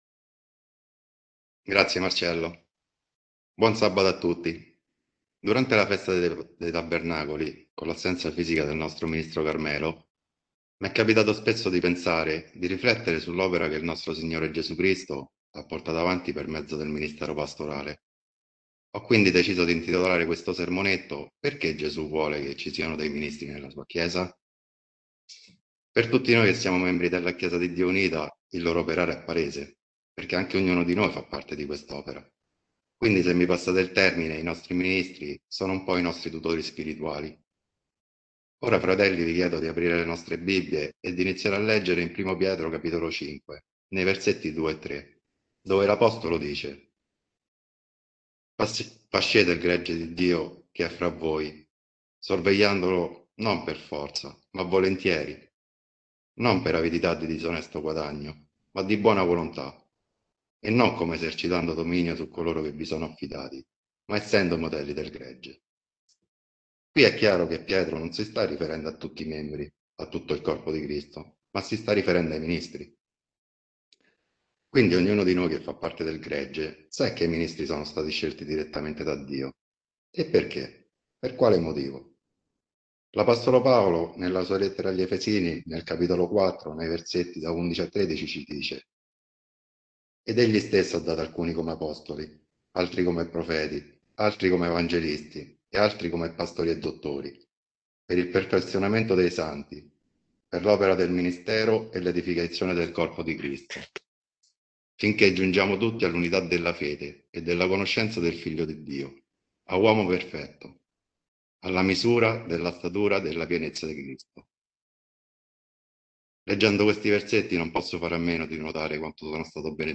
Sermonetto